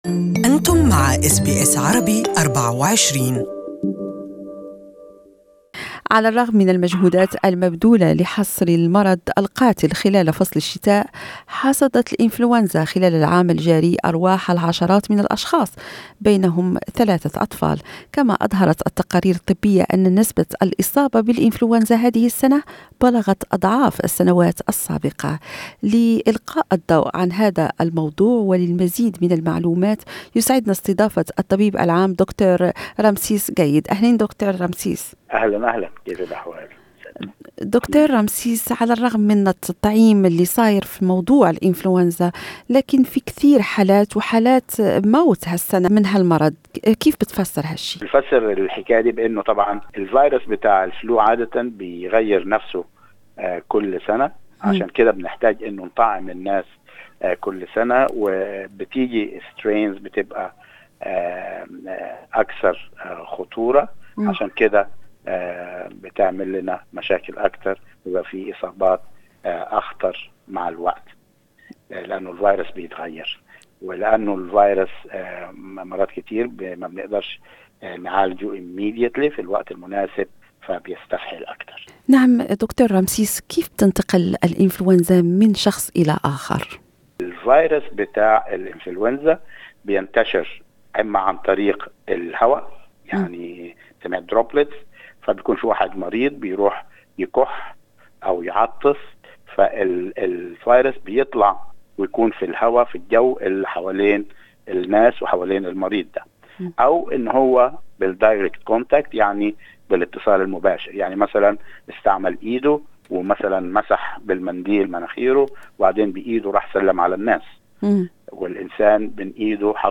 عن الوقاية من الإنفلونزا تحدثنا الى الطبيب العام